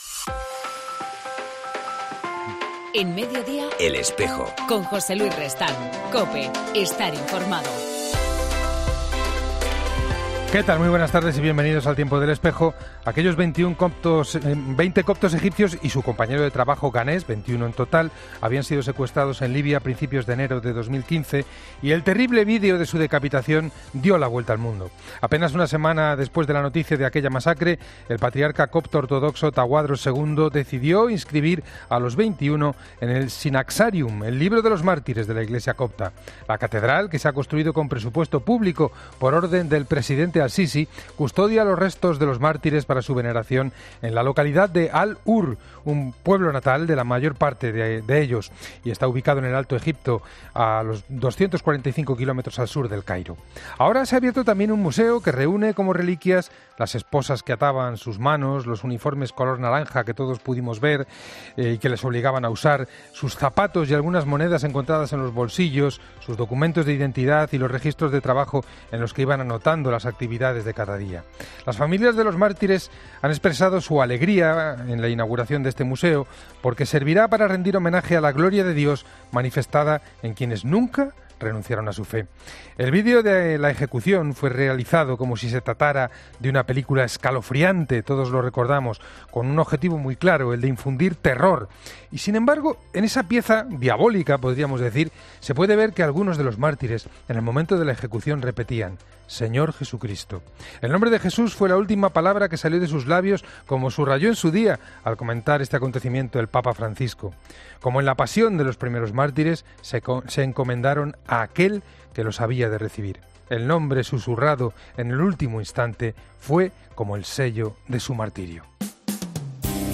AUDIO: En El Espejo contamos con el testimonio del Cardenal Leopoldo Brenes, arzobispo de Managua, con quien hablamos sobre la situación que...